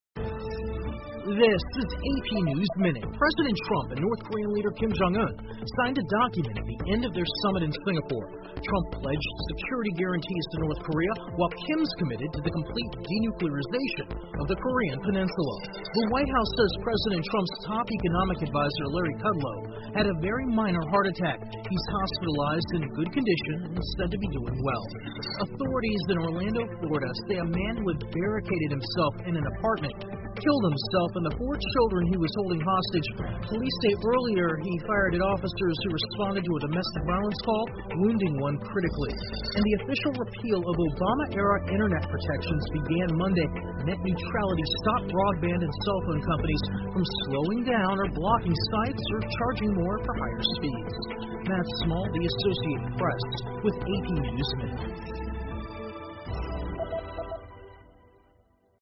美联社新闻一分钟 AP 特朗普金正恩签署文件 听力文件下载—在线英语听力室